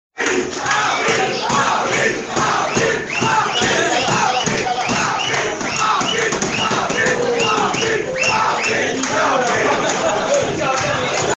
Au Sun Trust, on laisse éclater sa joie. Pravind Jugnauth a gagné son procès en appel.